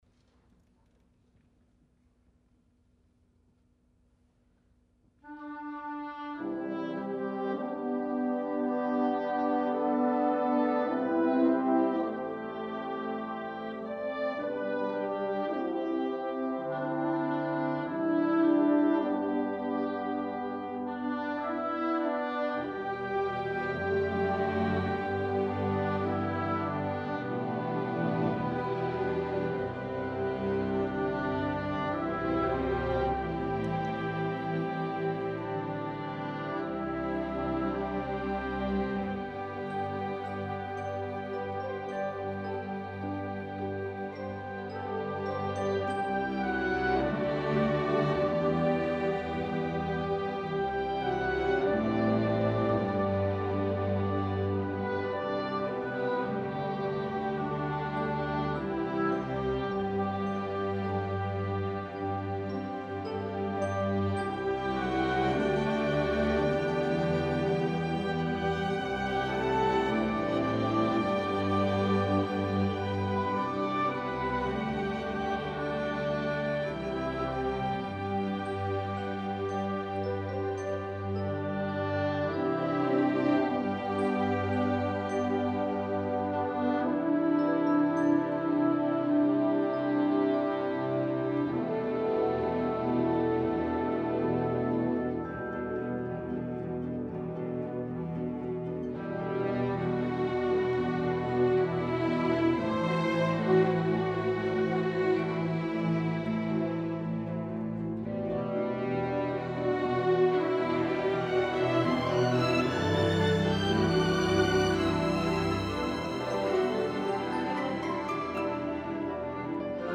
2020 UIL Symphony Orchestra — Liberty Band
Liberty HS Symphony Orchestra